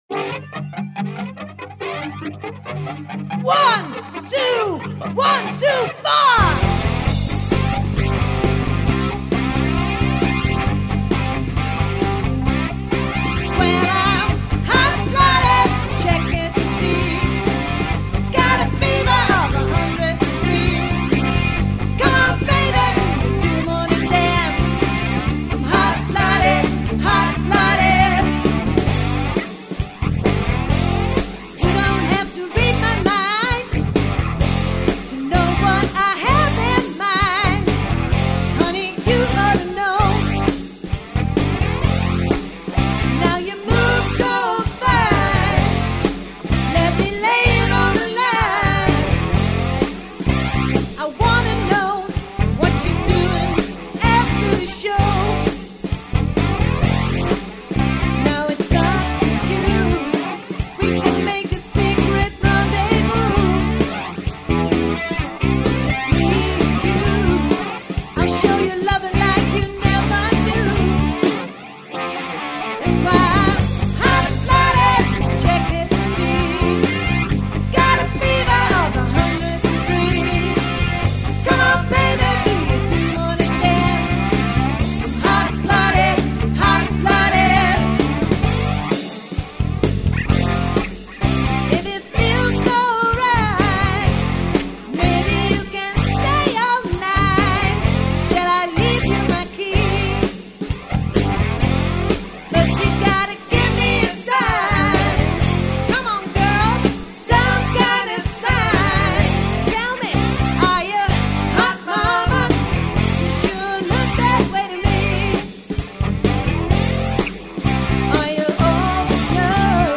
Rockband
Vocals
Guitars
Drums